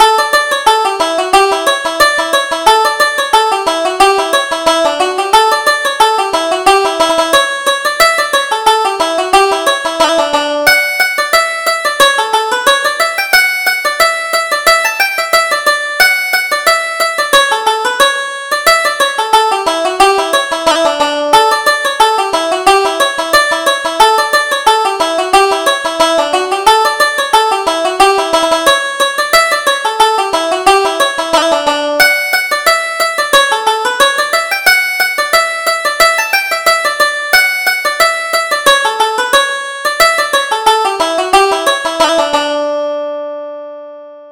Reel: Jenny Picking Cockles